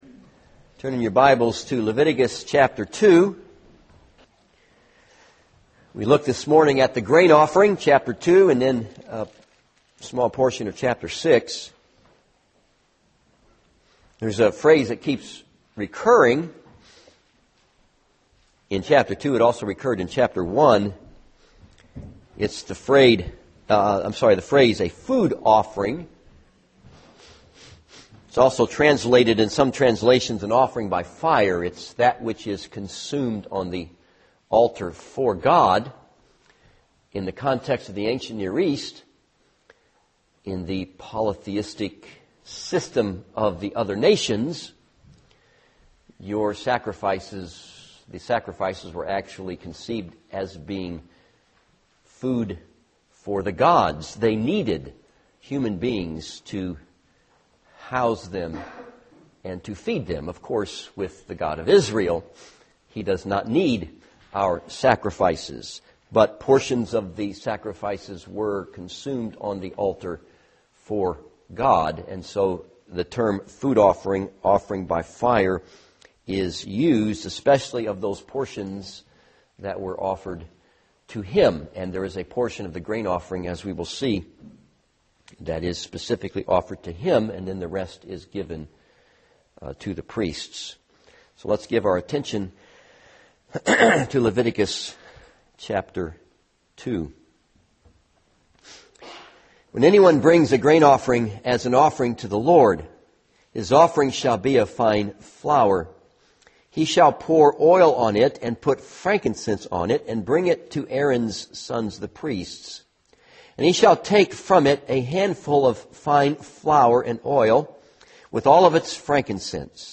This sermon is based on Leviticus 2 and Leviticus 6:14-18.